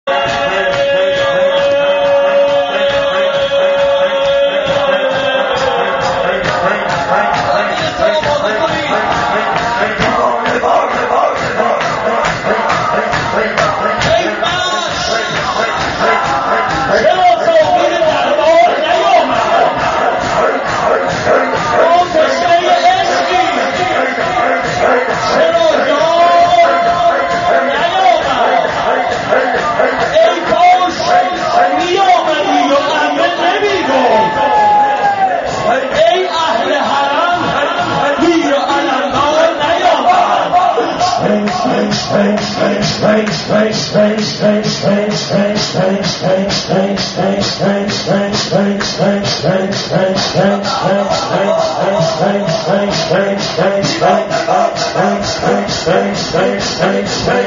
شعر خوانی و ذکر اباعبدالله.MP3